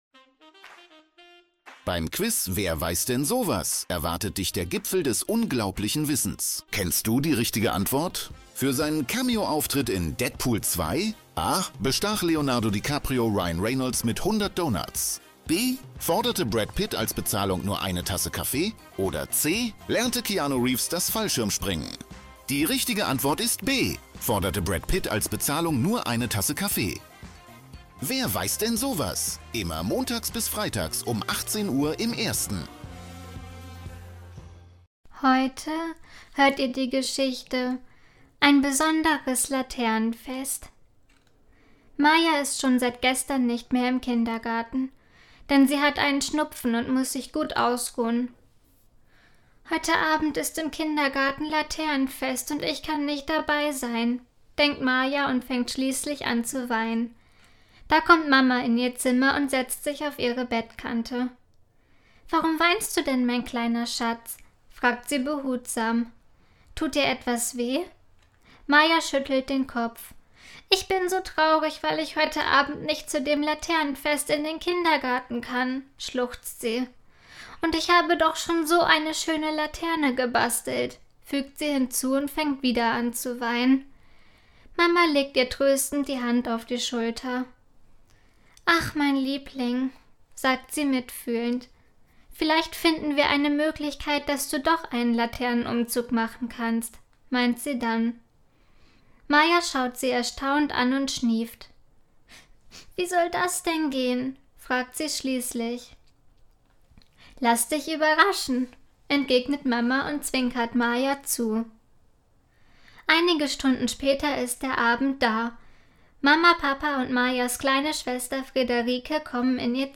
Kindergeschichten mit garantiertem Happy End